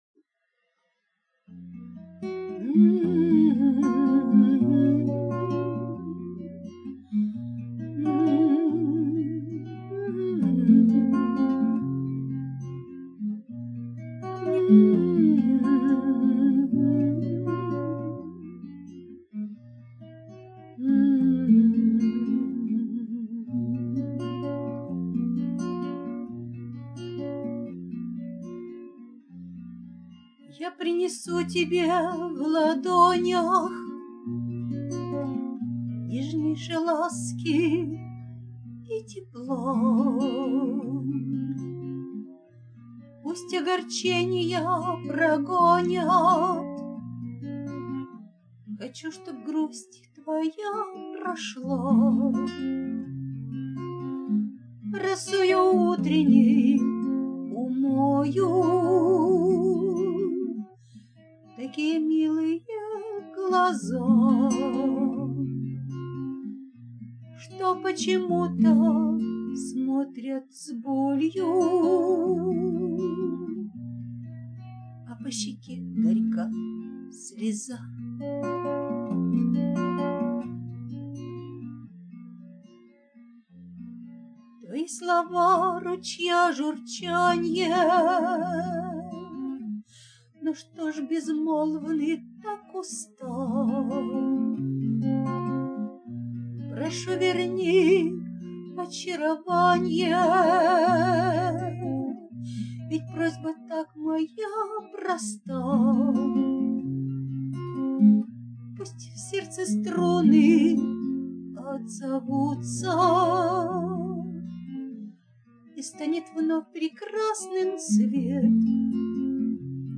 Нежный голос, замечательно! give_rose give_rose give_rose
Очень красиво!Романс получился замечательный.
Романс...